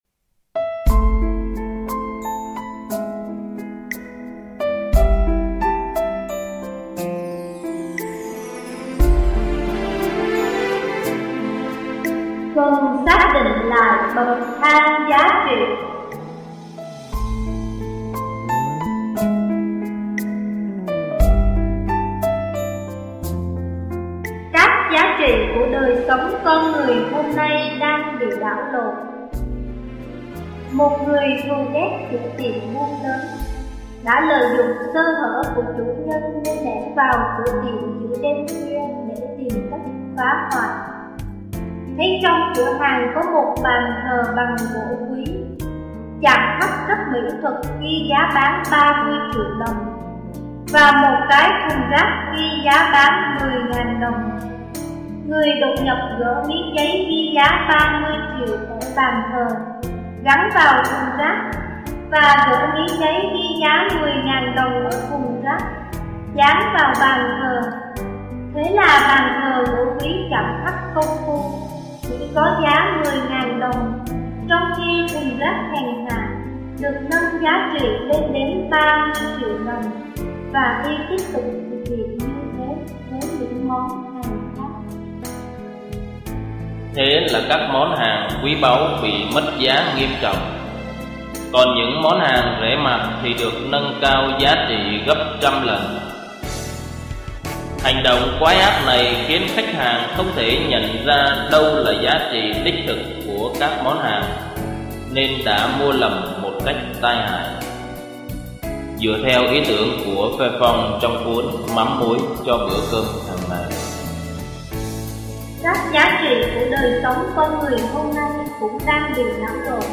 (Suy niệm Tin mừng Chúa Nhật XIV thường     niên năm B)